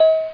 Marathon Terminal Sound 1 Sound Button - Free Download & Play